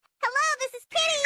click.mp3